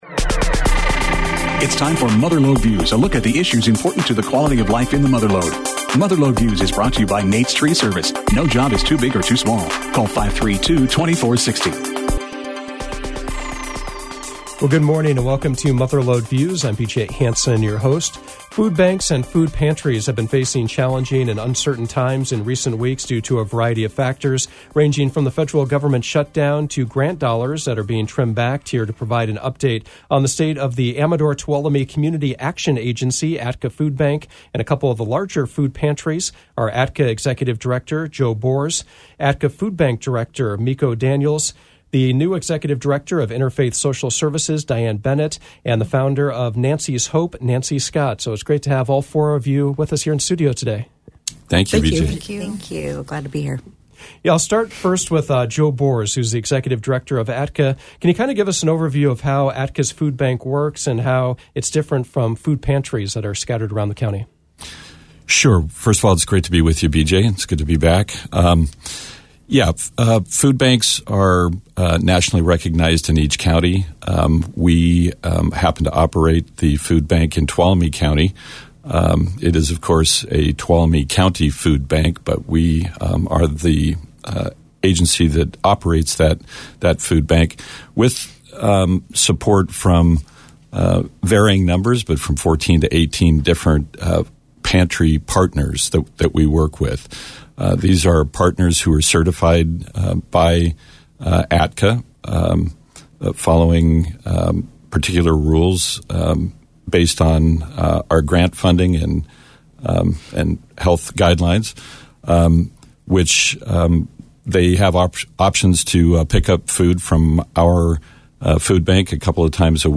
Mother Lode Views featured a panel of guests from local food banks and pantries talking about the current state of need in the community.